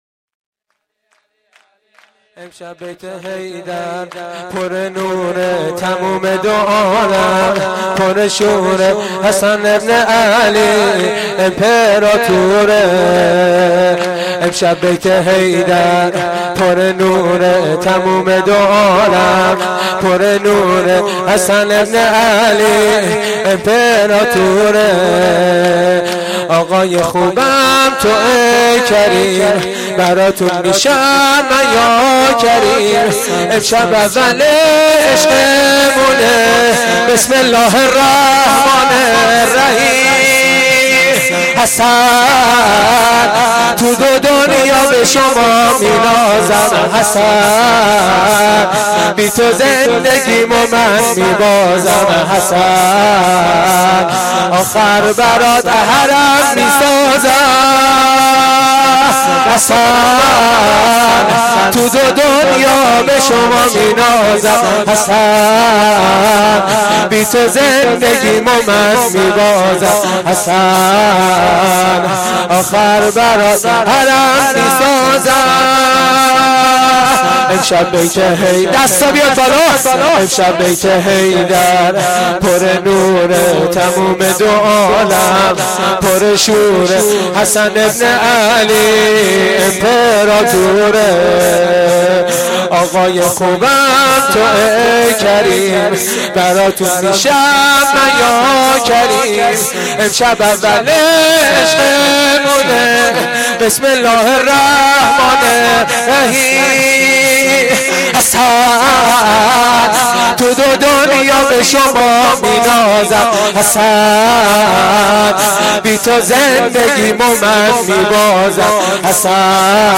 شور
shoor4-Rozatol-Abbas.Milad-Emam-Hasan.mp3